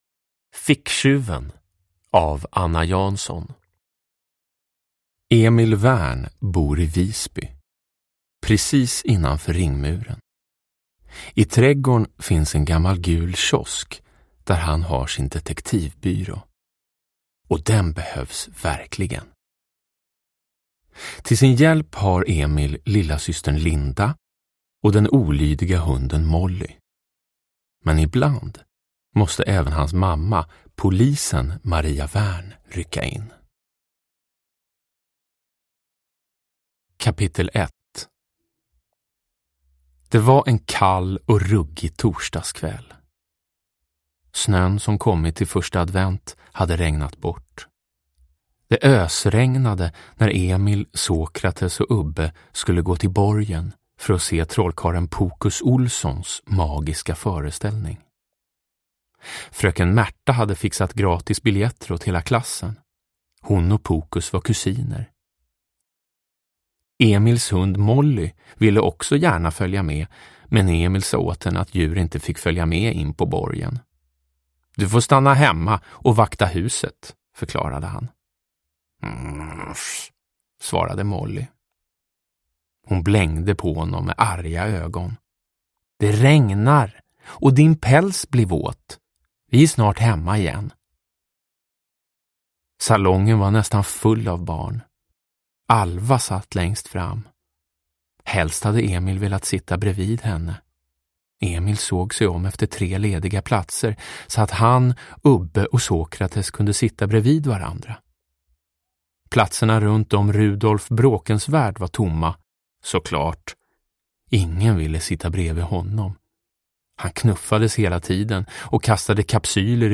Ficktjuven – Ljudbok – Laddas ner
Uppläsare: Jonas Karlsson